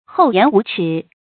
hòu yán wú chǐ
厚颜无耻发音
成语注音 ㄏㄡˋ ㄧㄢˊ ㄨˊ ㄔㄧˇ
成语正音 耻，不能读作“zhǐ”。